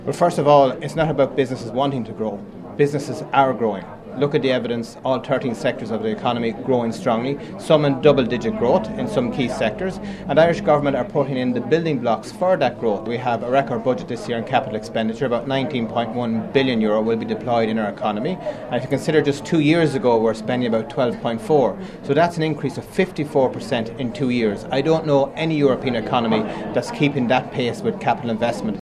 Enterprise Minister Peter Burke says several areas of the economy have seen significant growth……………….